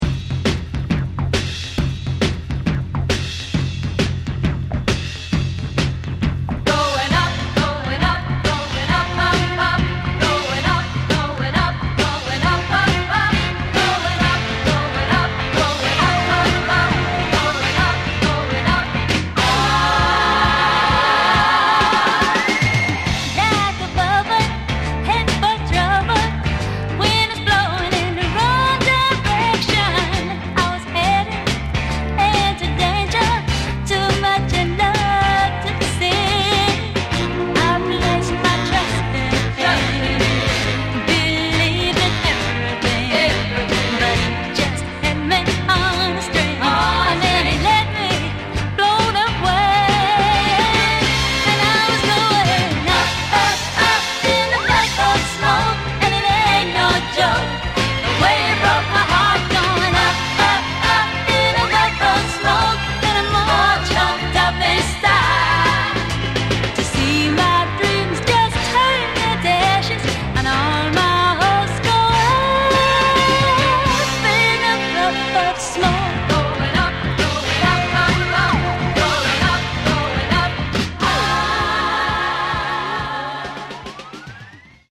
white British soul singer who sounds a bit like Diana Ross
It has pristine Mint labels and flawless audio.